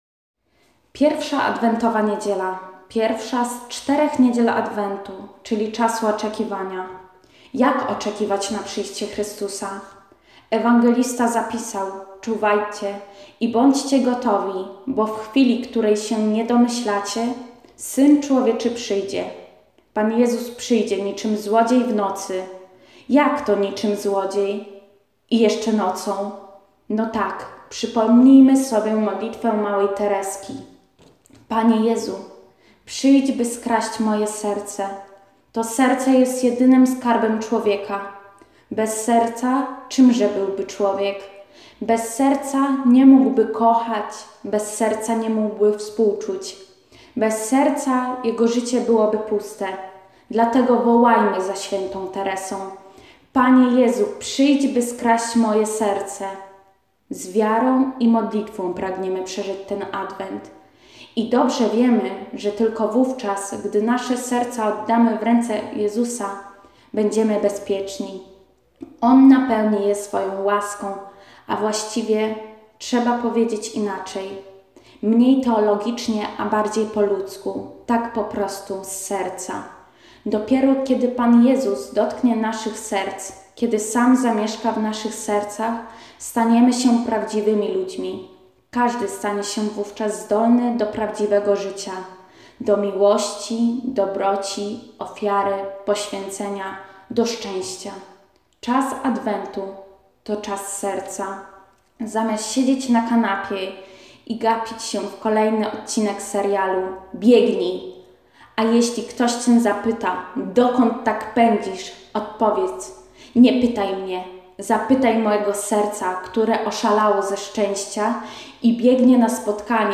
Komentarz do Ewangelii